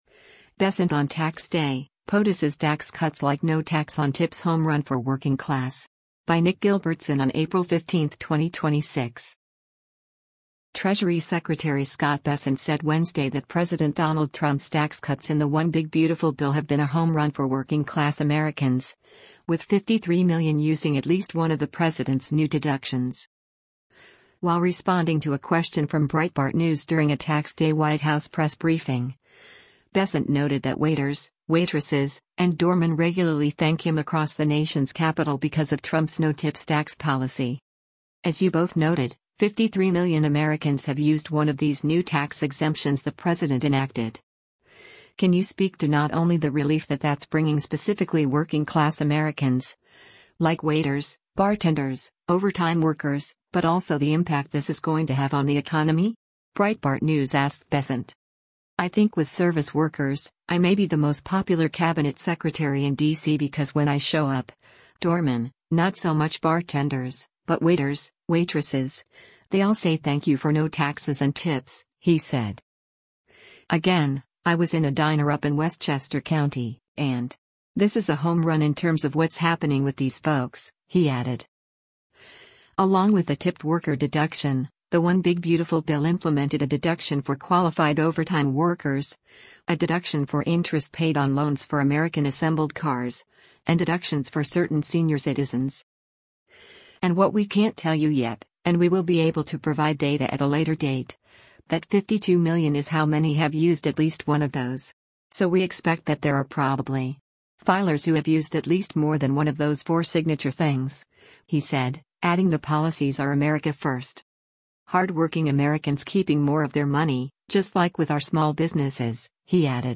While responding to a question from Breitbart News during a Tax Day White House press briefing, Bessent noted that waiters, waitresses, and doormen regularly thank him across the nation’s capital because of Trump’s no-tips tax policy.